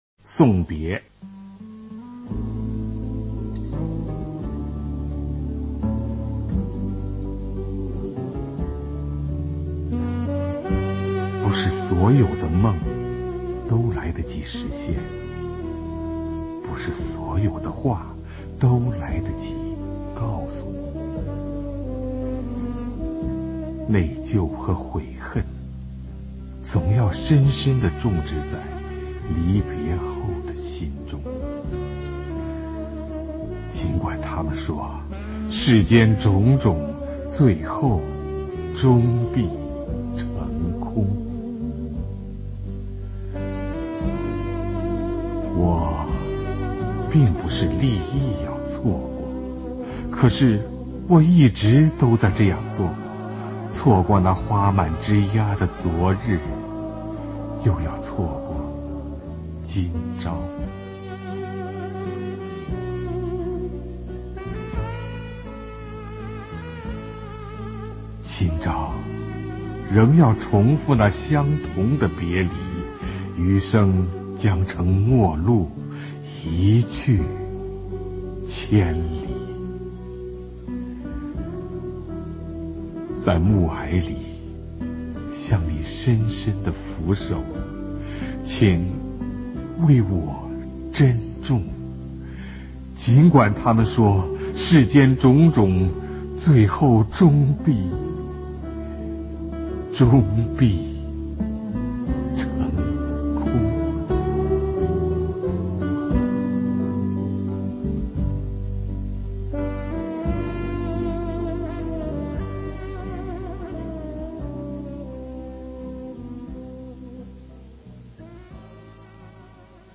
首页 视听 经典朗诵欣赏 席慕容：委婉、含蓄、文雅